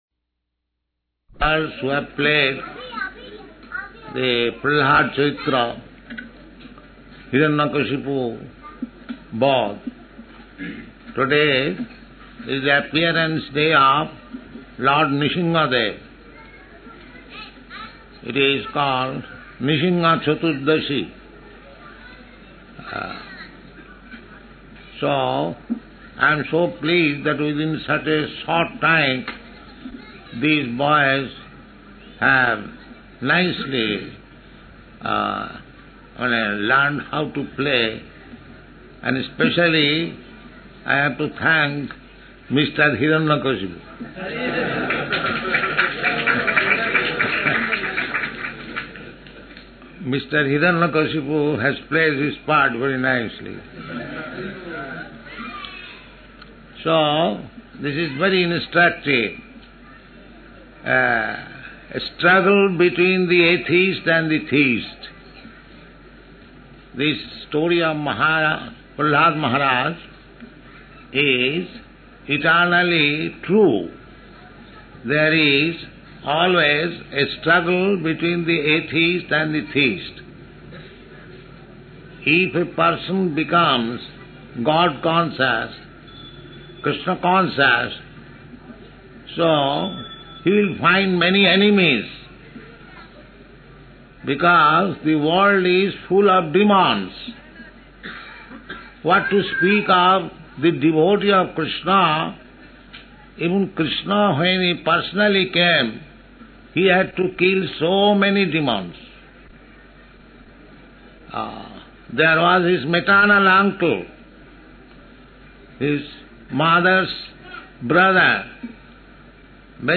Srila Prabhupada Lecture on the Appearance Day of Lord Nrsimhadeva, Bombay, May 05, 1974